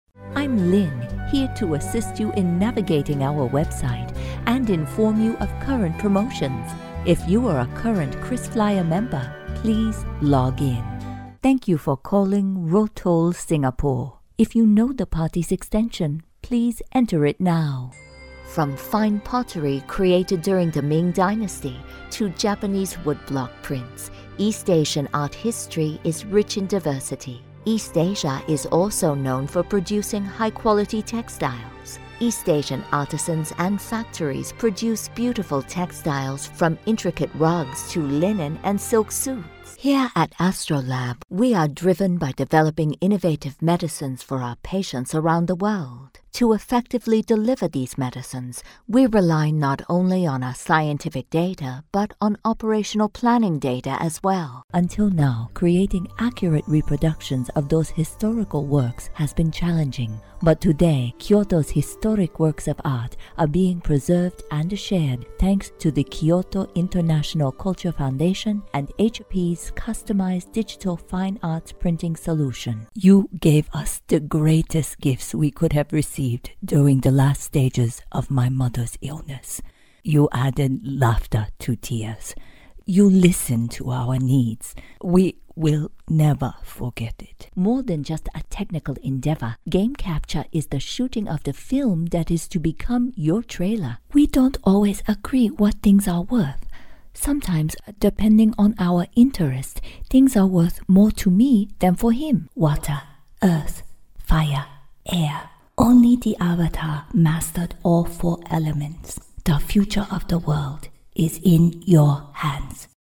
Demo
Mature Adult, Adult
Has Own Studio
Warm, knowledgeable, real, inviting, conversational, believable, friendly, genuine, upbeat, upscale, smooth, fun, classy, confident, authoritative, professional, silky, sultry, energetic, dynamic, dramatic, playful, educated, humorous, sexy, smart, sassy, bubbly, classic!
Top quality home studio, available on short notice, fast turnaround (usually within a couple of hours).
asian